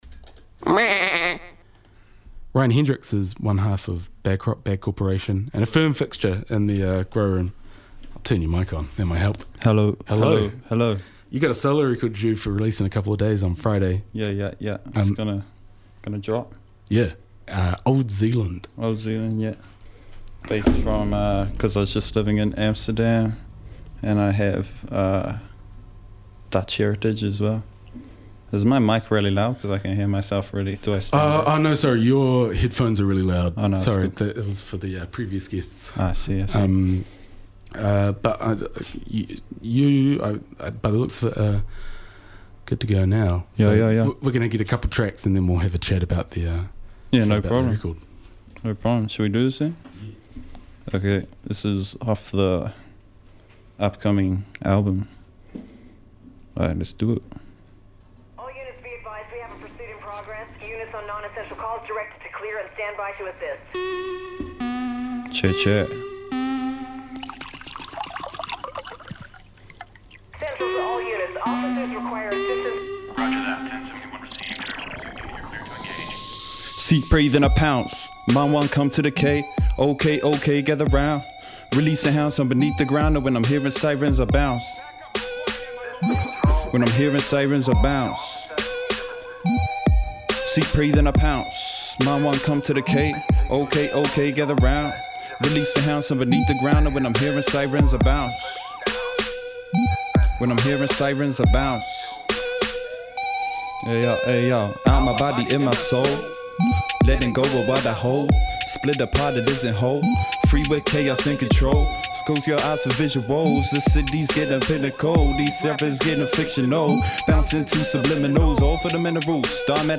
in studio for a live performance